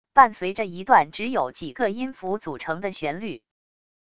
伴随着一段只有几个音符组成的旋律wav音频